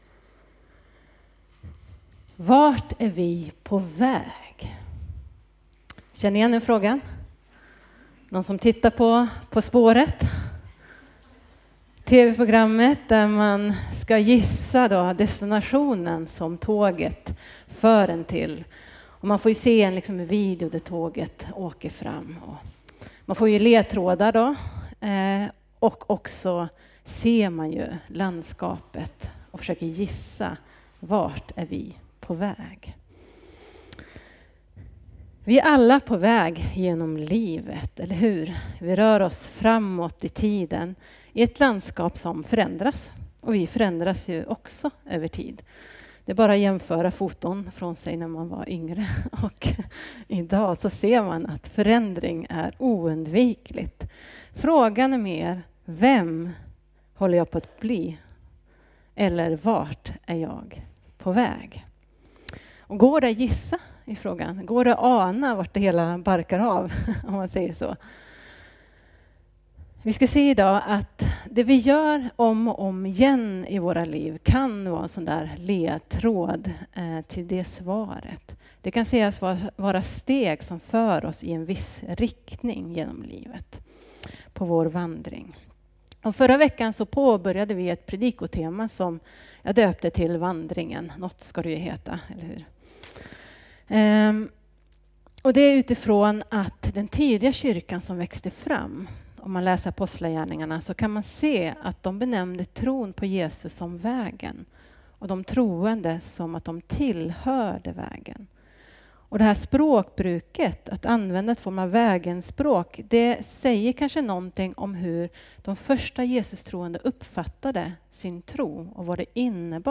Predikningar Elimkyrkan Gammelstad